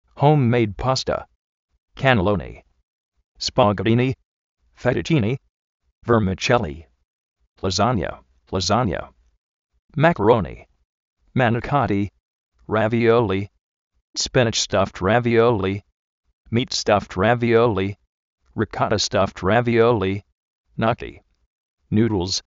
Vocabulario en ingles, diccionarios de ingles sonoros, con sonido, parlantes, curso de ingles gratis
jóum-méid pásta
kanelóni
máka-roni
nú:dls